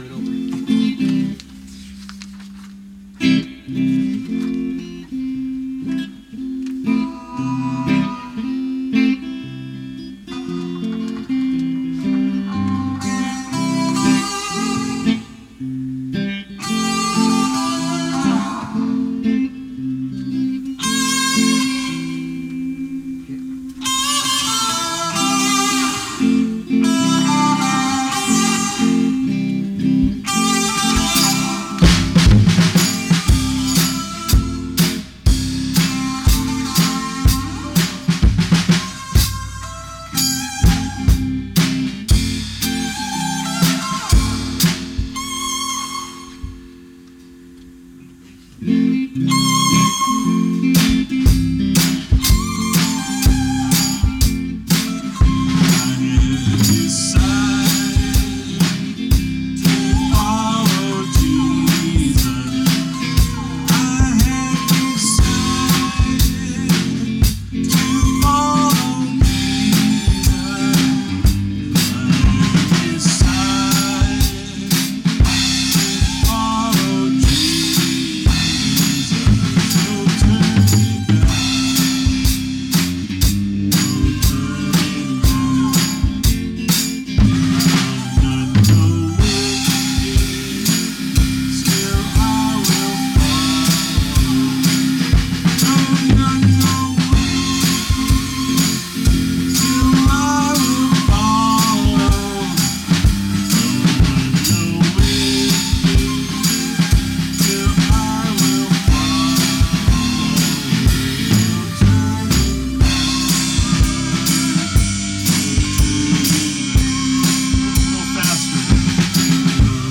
Vocal & Bass
Drums